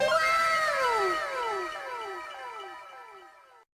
sfx_wow.ogg